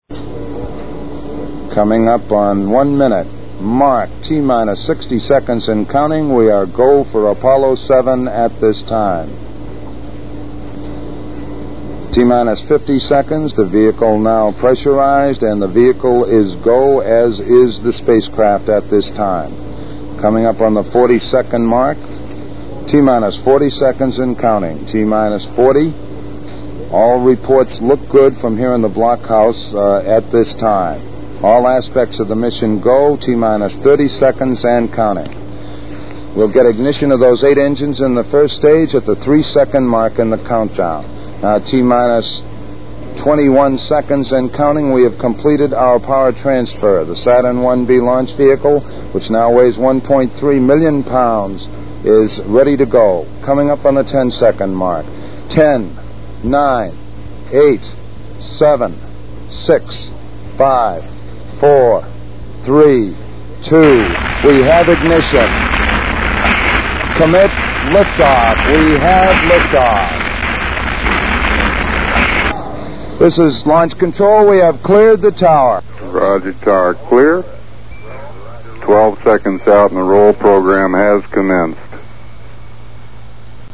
Tags: ORIGINAL COMMUNICATIONS APOLLO MISSIONS NASA